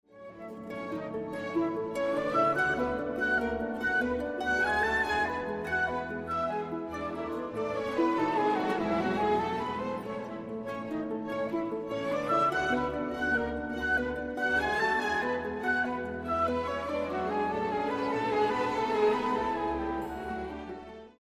Extraits du concert